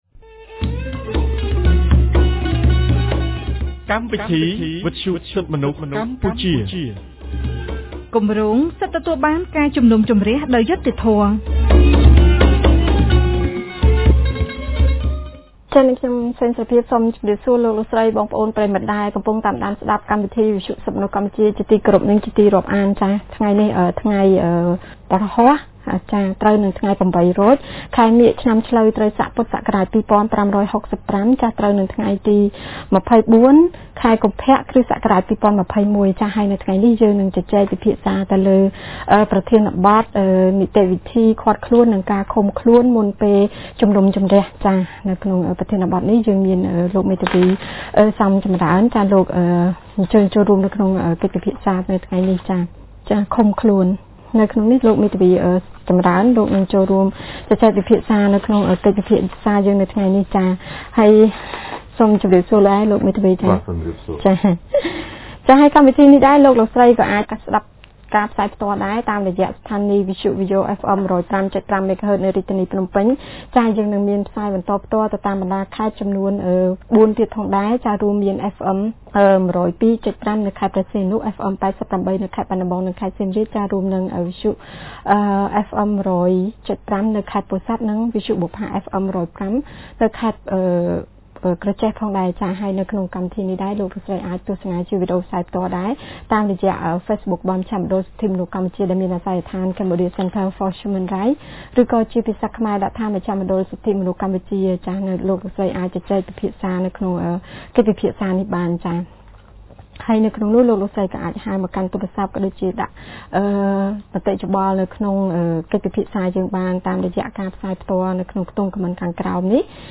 ថ្ងៃទី២៤ ខែកុម្ភៈ ឆ្នាំ២០២២ គម្រាងសិទ្ធិទទួលបានការជំនុំជម្រះដោយយុត្តិធម៌នៃមជ្ឈមណ្ឌលសិទ្ធិមនុស្សកម្ពុជា បានរៀបចំកម្មវិធីវិទ្យុក្រោមប្រធានបទស្តីពី នីតិវិធីឃាត់ខ្លួន និងឃុំខ្លួនមុនពេលជំនុំជម្រះ។